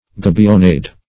Gabionnade \Ga`bion`nade"\, n.